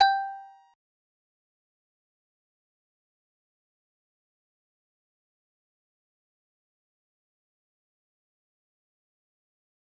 G_Musicbox-G5-pp.wav